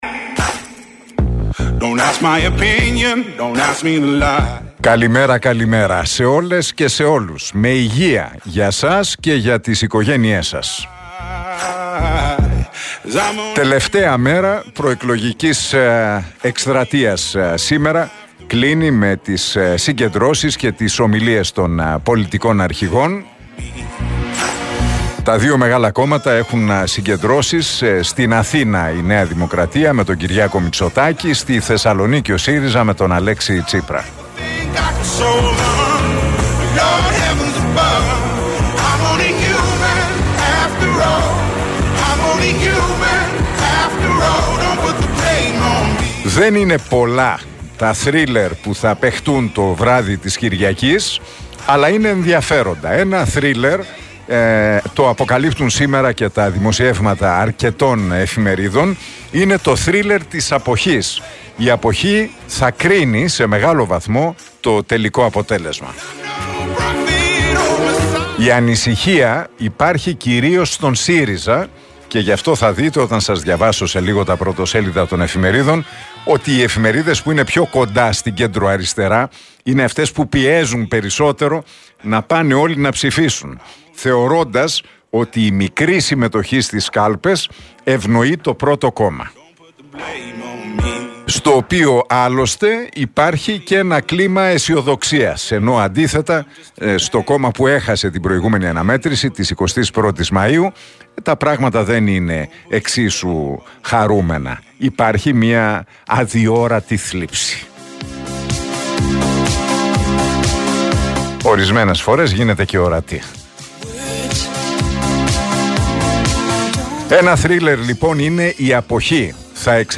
Ακούστε το σχόλιο του Νίκου Χατζηνικολάου στον RealFm 97,8, την Παρασκευή 22 Ιουνίου 2023.